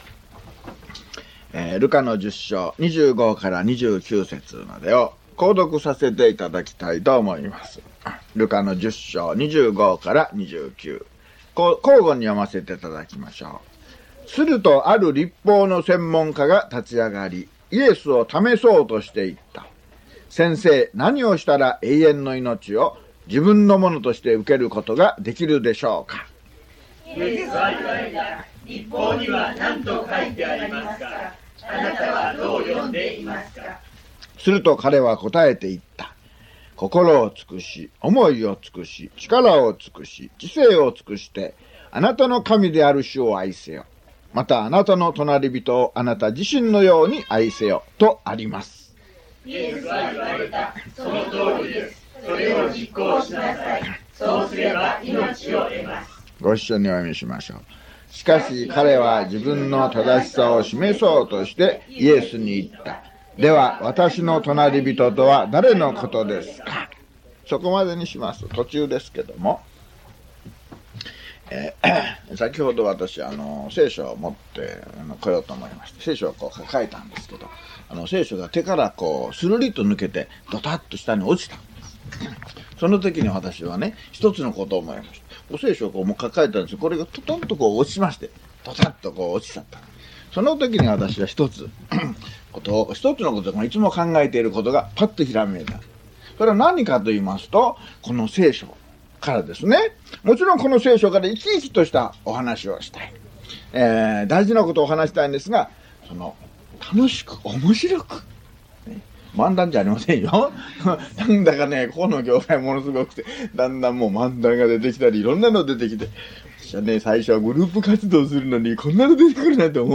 luke075mono.mp3